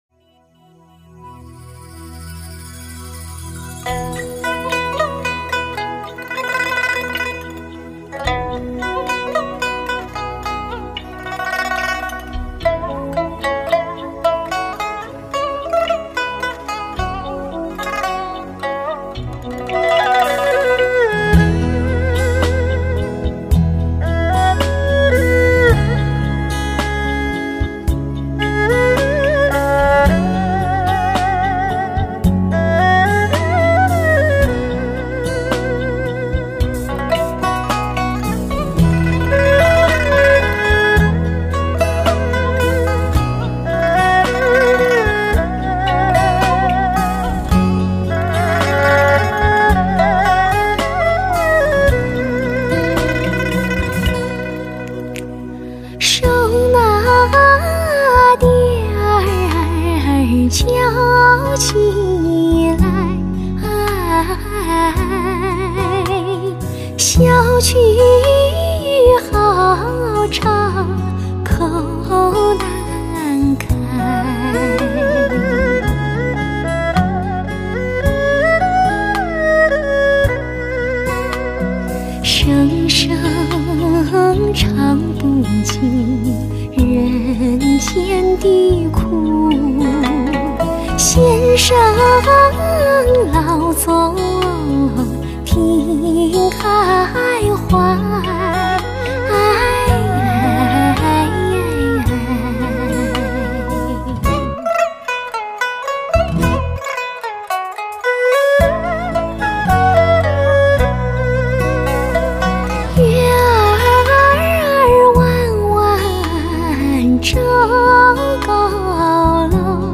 类型: 天籁人声
未加雕琢的深情演绎，靓绝的震撼录音